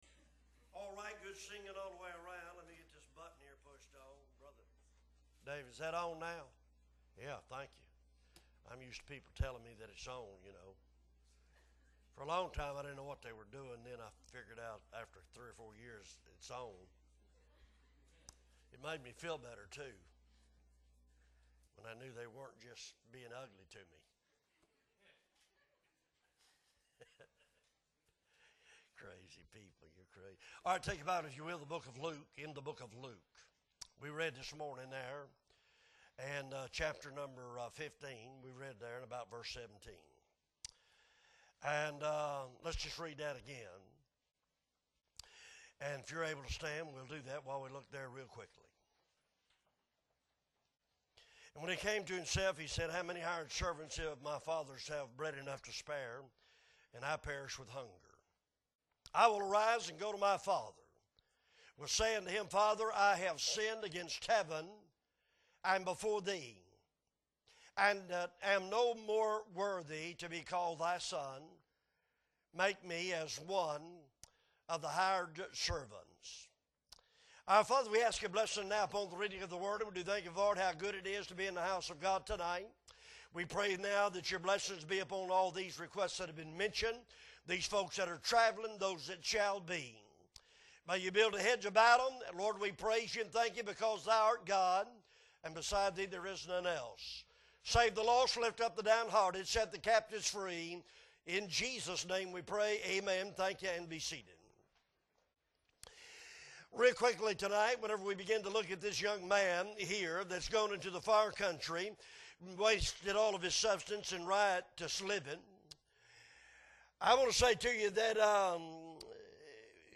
September 11, 2022 Evening Service - Appleby Baptist Church